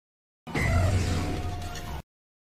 Cri d'Ire-Foudre dans Pokémon Écarlate et Violet.